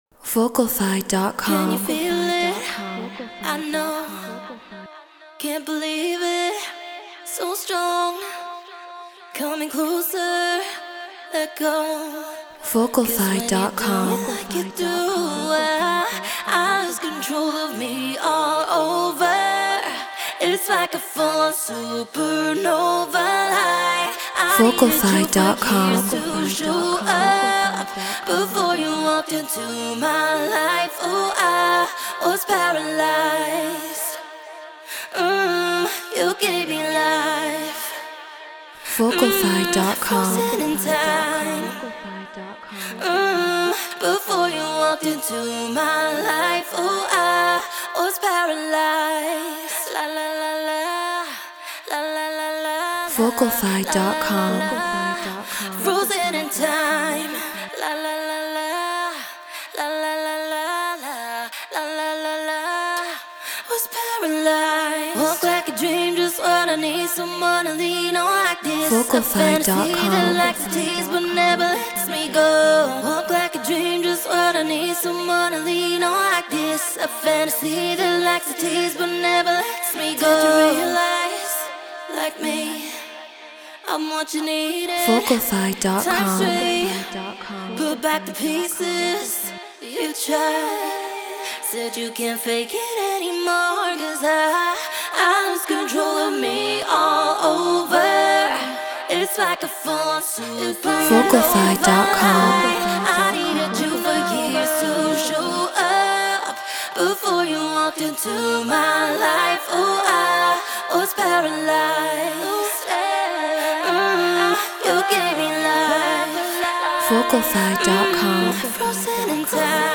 House 122 BPM D#maj
Human-Made
SE2200A MKII Presonus Studio 24 Logic Pro Treated Room